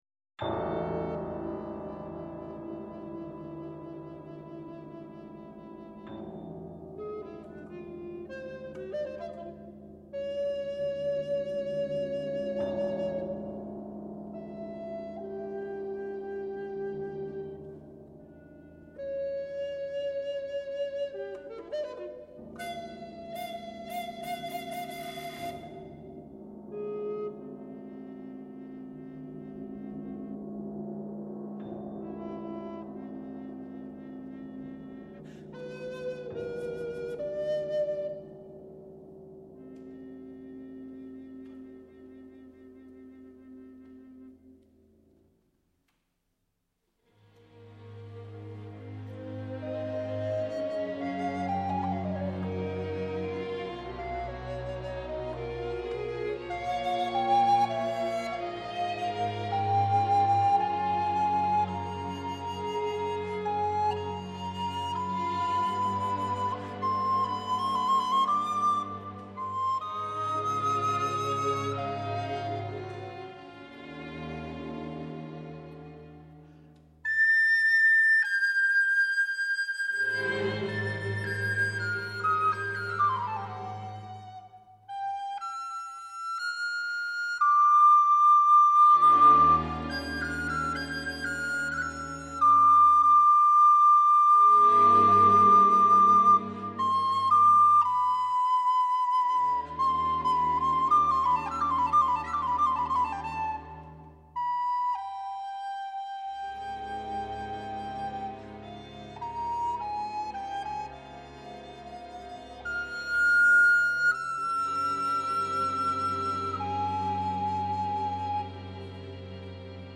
piano
percussion
Introduzione e allegro